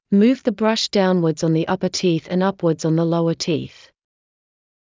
ﾑｰﾌﾞ ｻﾞ ﾌﾞﾗｯｼ ﾀﾞｳﾝﾜｰｽﾞ ｵﾝ ｼﾞ ｱｯﾊﾟｰ ﾃｨｰｽ ｴﾝﾄﾞ ｱｯﾌﾟﾜｰｽﾞ ｵﾝ ｻﾞ ﾛｳｱｰ ﾃｨｰｽ